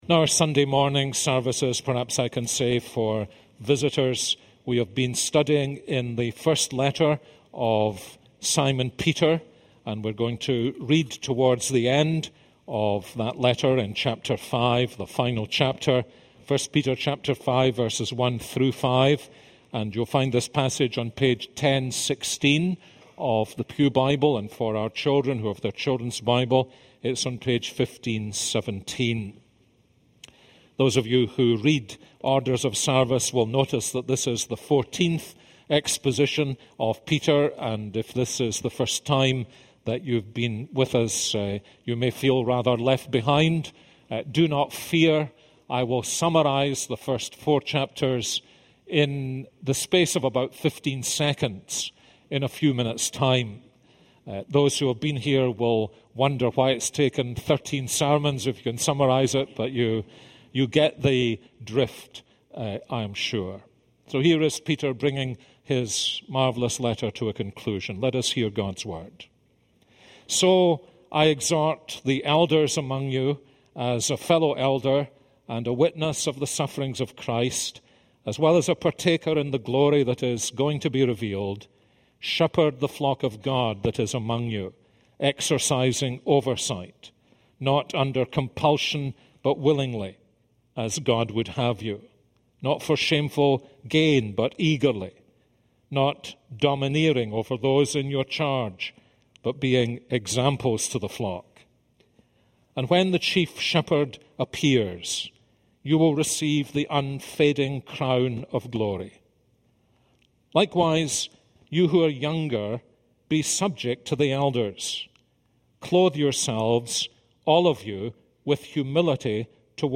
This is a sermon on 1 Peter 5:1-5.